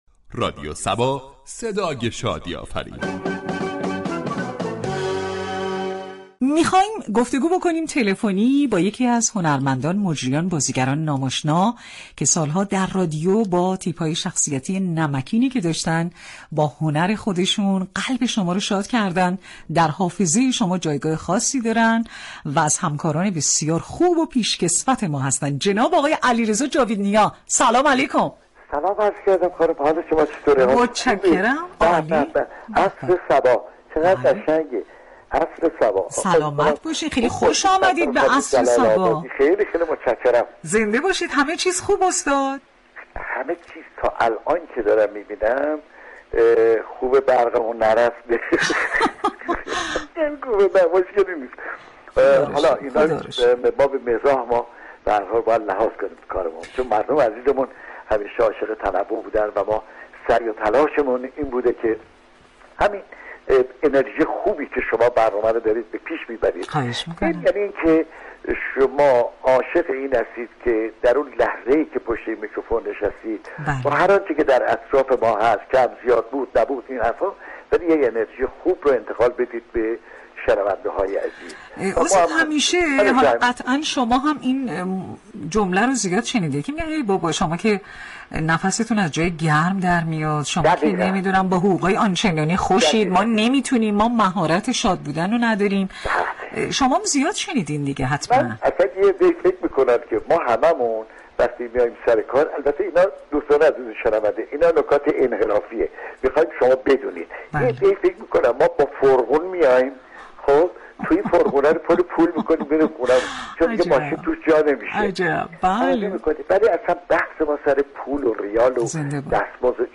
گفتگوی